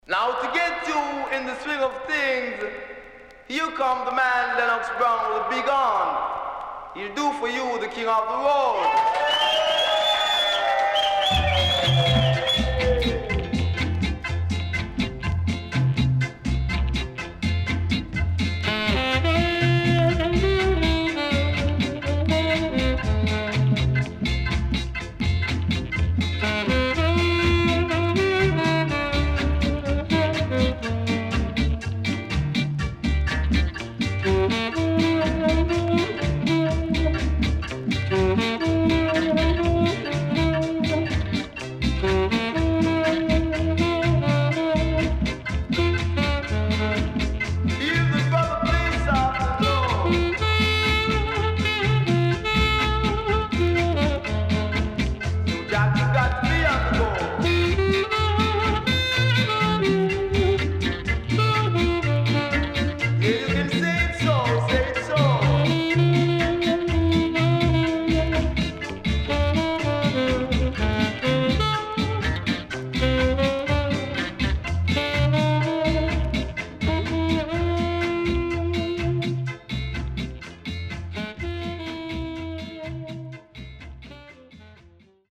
CONDITION SIDE A:VG(OK)〜VG+
SIDE A:全体的にかるいチリノイズがあり、少しプチノイズ入ります。